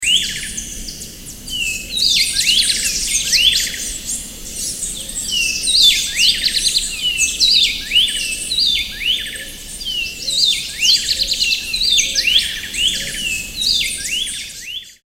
水琴窟 T